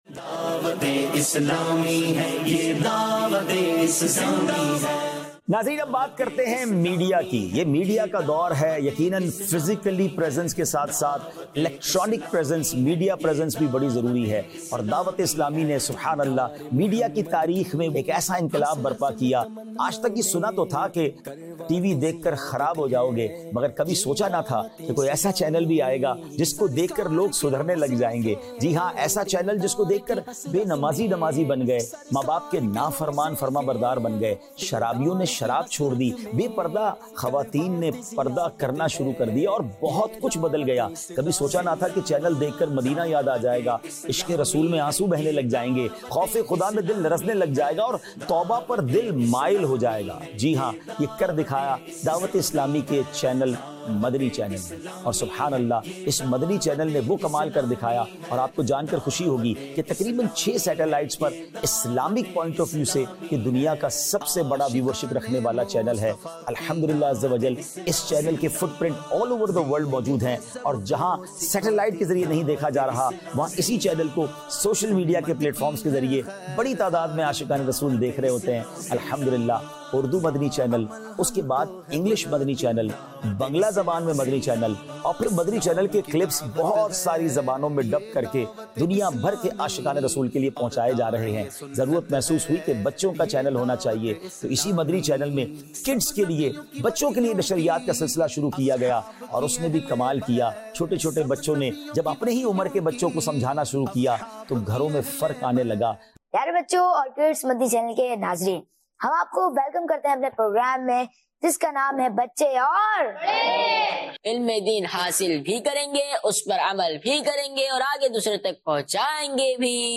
Social Media | Department of Dawateislami | Documentary 2026